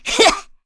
Erze-Vox_Attack3.wav